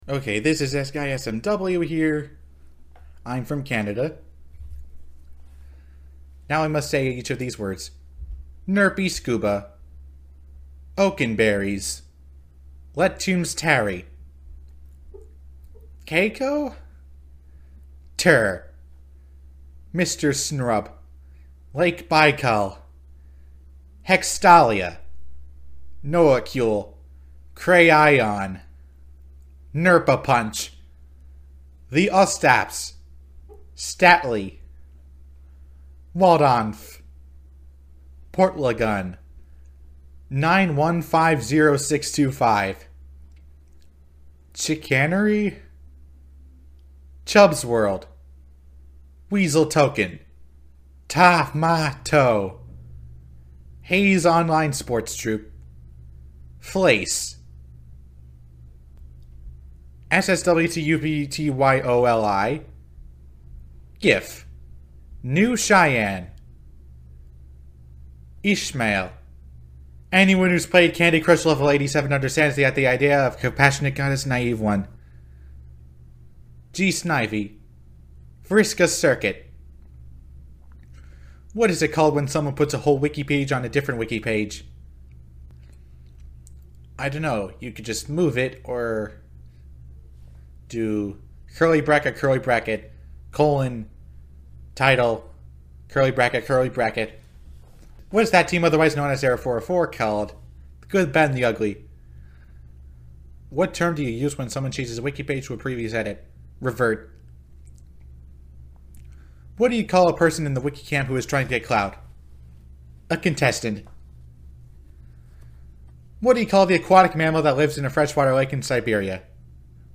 Recordings of ourselves answering those questions.